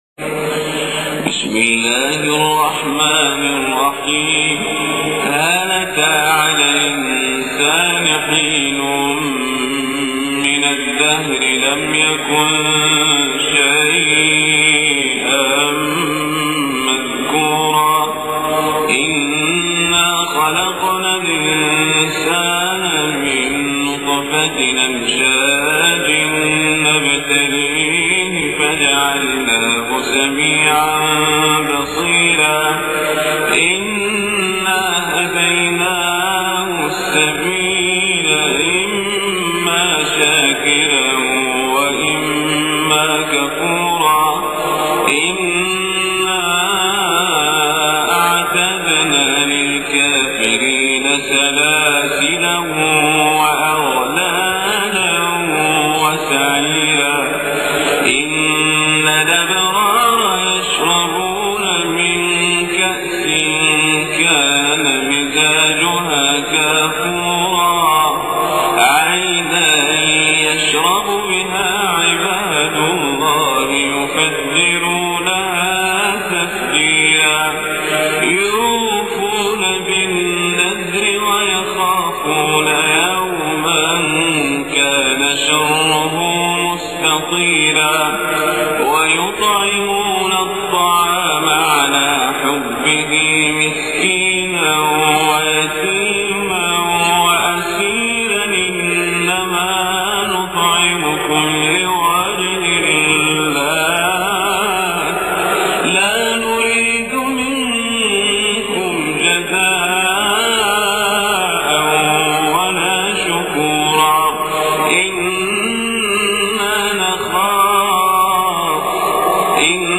سورة الإنسان.....من صلاة الفجر....الجمعة.....القزابري
إليكم هذه التلاوة لسورة الإنسان